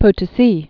(pō-tə-sē, -tō-)